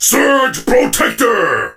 surge_start_vo_05.ogg